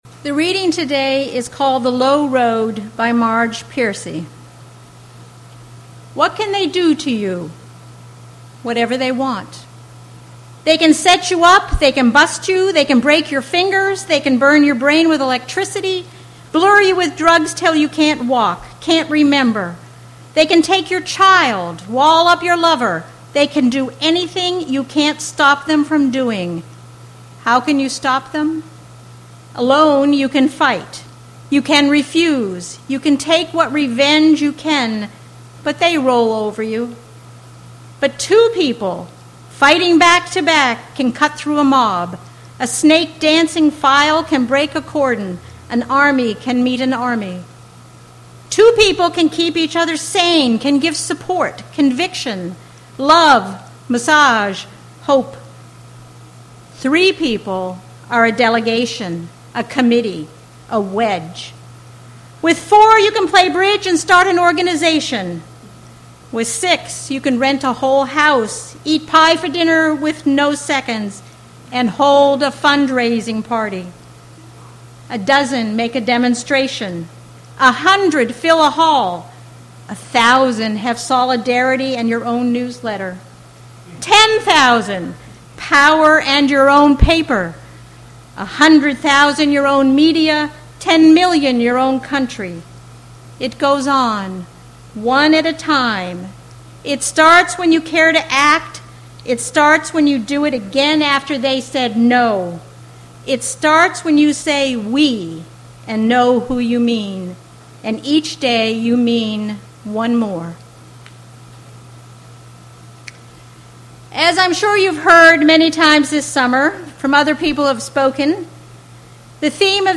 This sermon highlights the Unitarian Universalist belief that every individual possesses the potential and responsibility to act as a prophet for social justice.